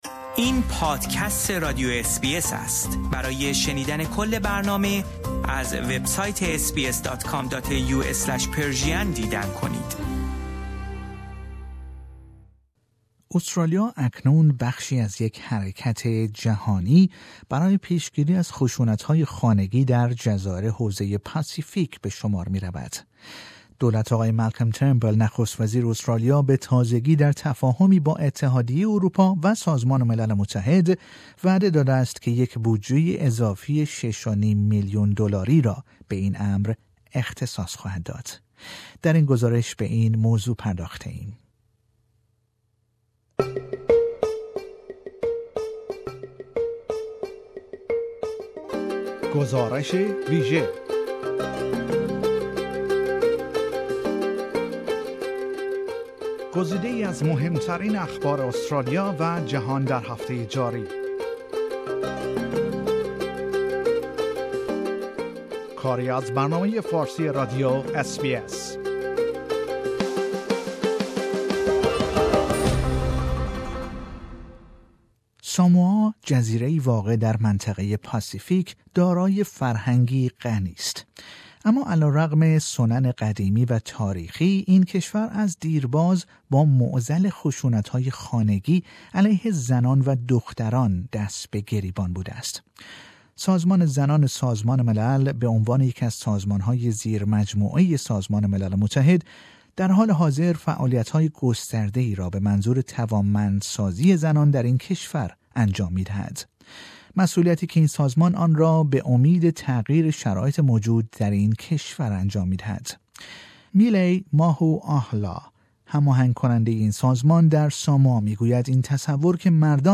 در این گزارش به این موضوع پرداخته ایم.